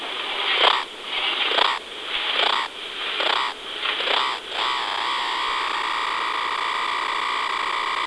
Cicada Song
Cicadas are familiar in Brisbane because their 'songs' are the back ground noise here in summer.
Sound of Floury Baker Cicada.
This is the male who sing the song to attract female.
FlouryBakerSong.wav